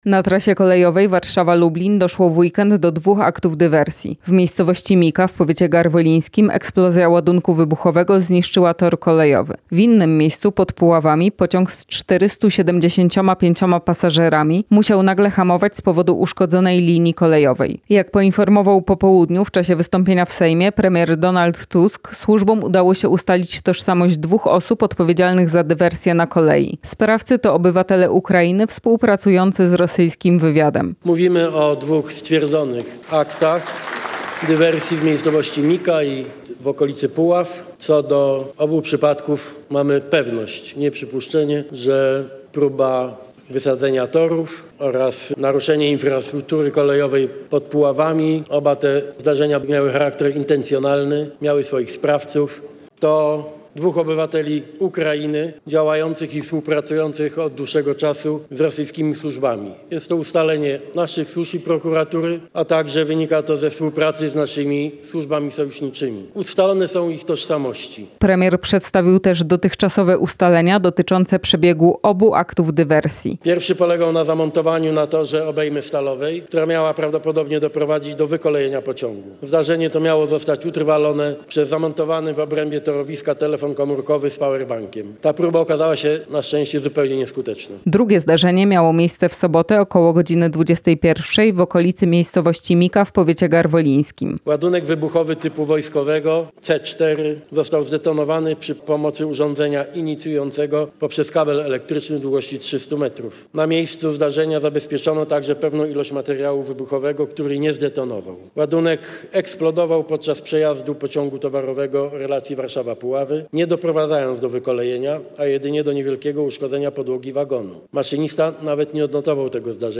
Jak poinformował w czasie wystąpienia w Sejmie premier Donald Tusk, służbom udało się ustalić tożsamość dwóch osób odpowiedzialnych za dywersję na kolei.